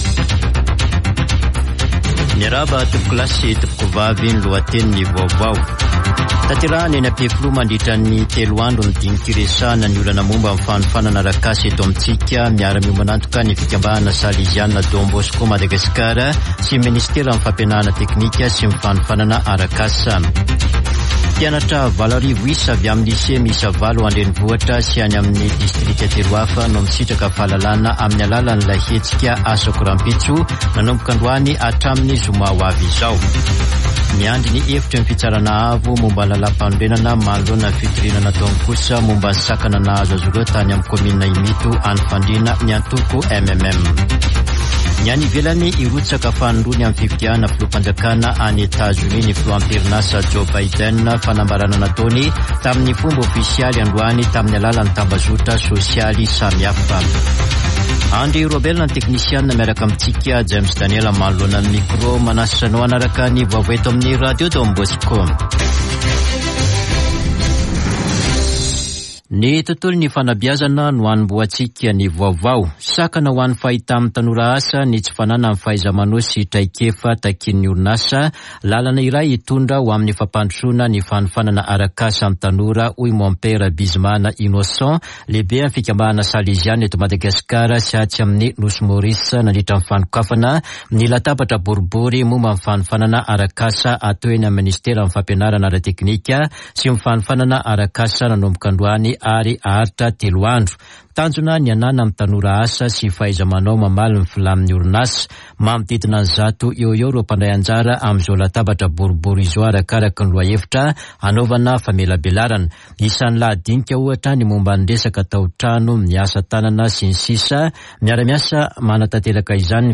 [Vaovao hariva] Talata 25 aprily 2023